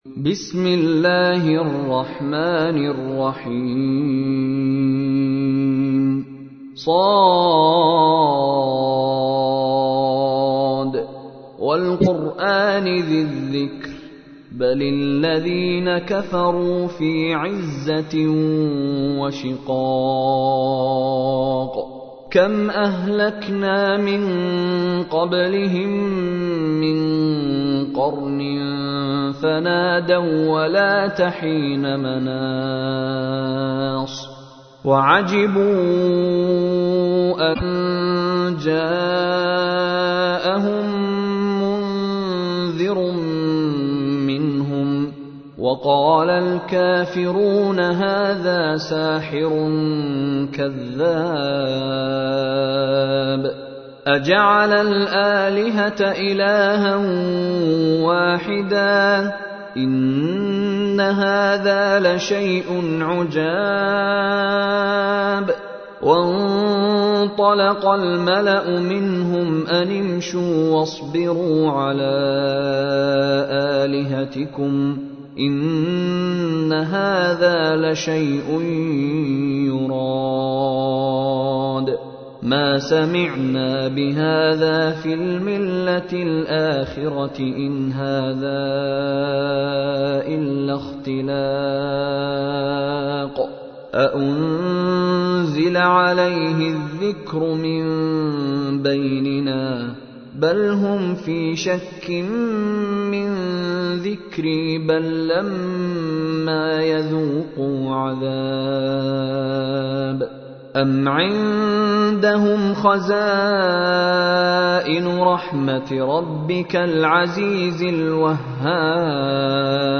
تحميل : 38. سورة ص / القارئ مشاري راشد العفاسي / القرآن الكريم / موقع يا حسين